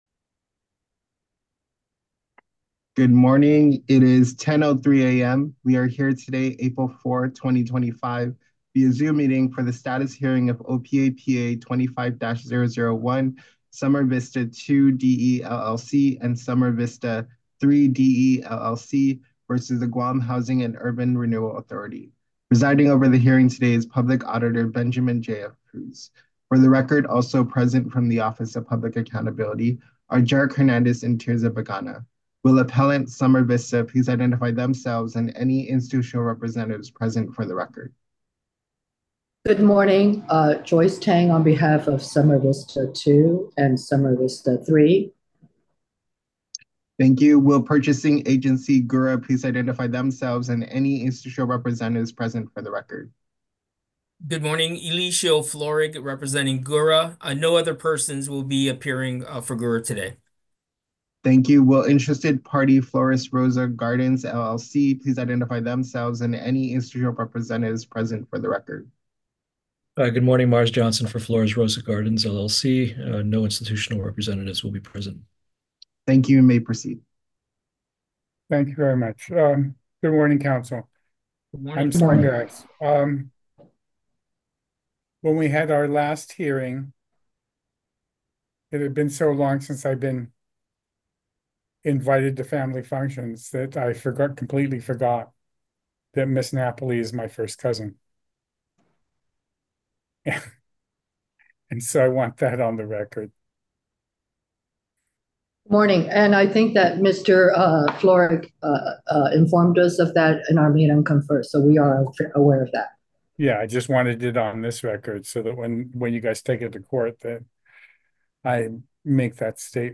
Status Hearing - April 4, 2025